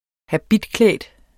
Udtale [ haˈbidˌklεˀd ]